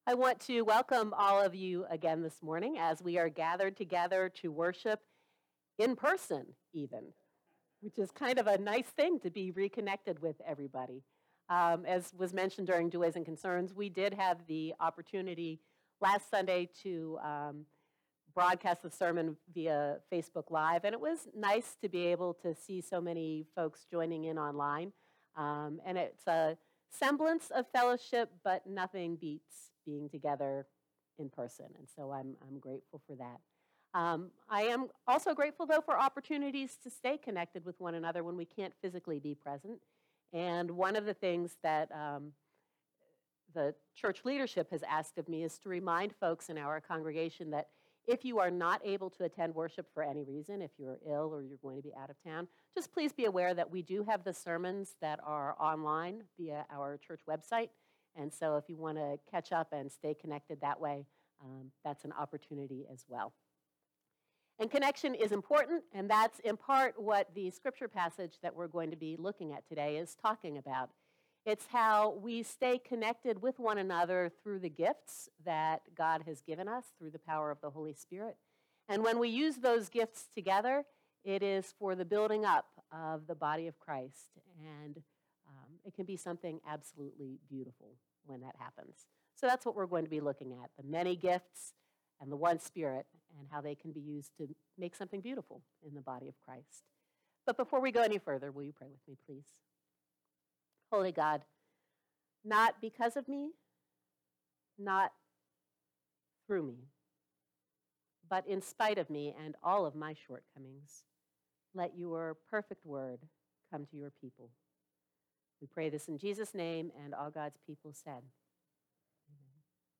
Morrisville United Methodist Church Sermons